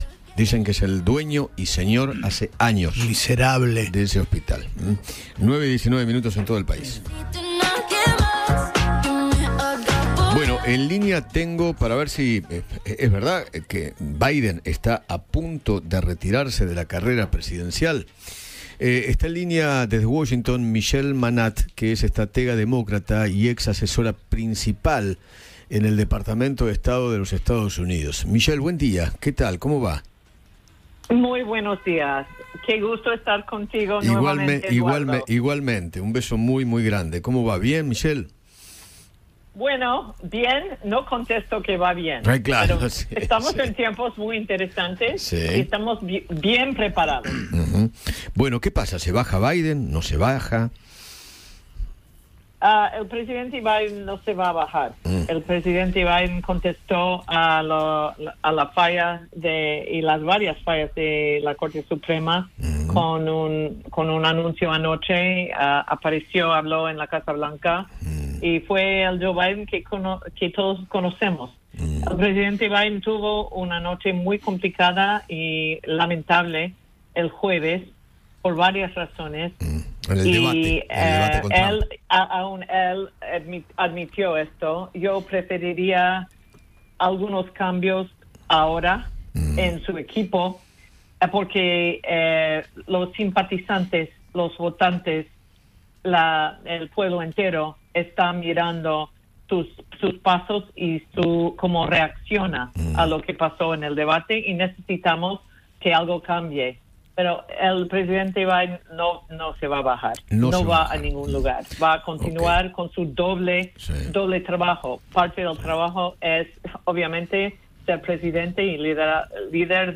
habló con Eduardo Feinmann sobre la candidatura de Joe Biden.